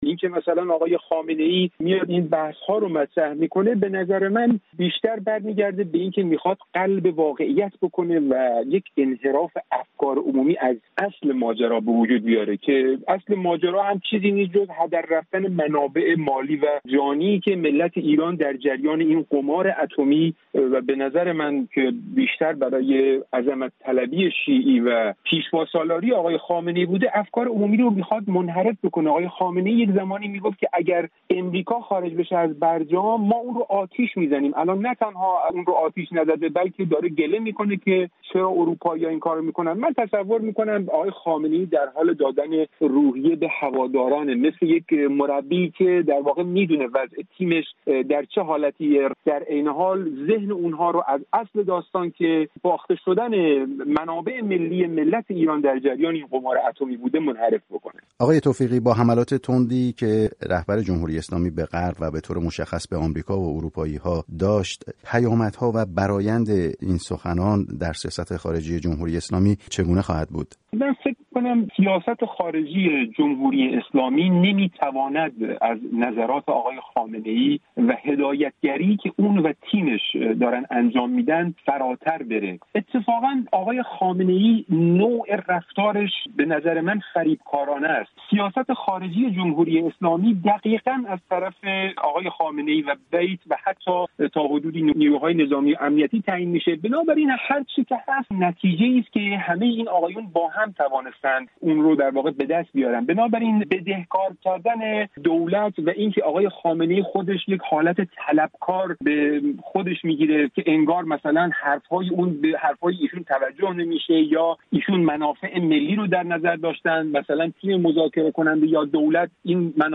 ارزیابی انتقادهای خامنه‌ای از «نشست ورشو» و اروپا در گفت‌وگو